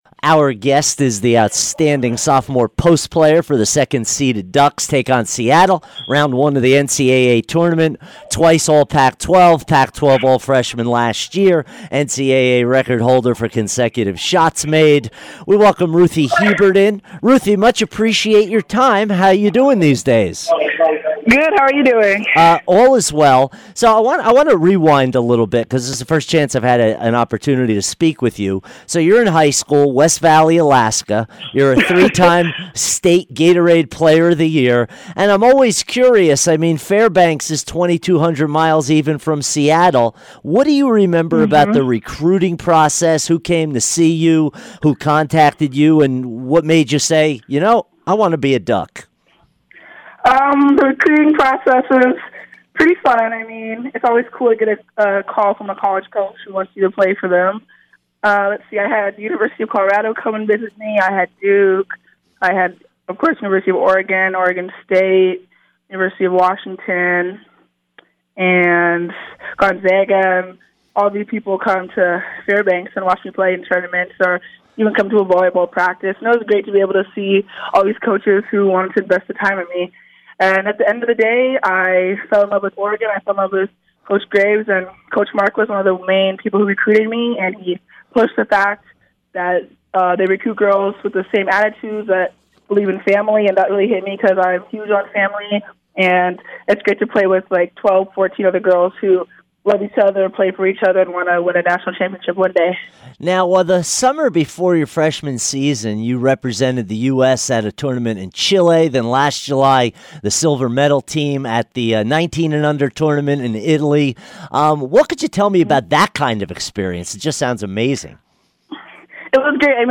Ruthy Hebard Interview 3-14-18